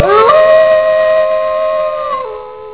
Listed below are samples that I have found of wolves howling.
wolf5.wav